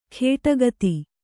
♪ khēṭa gati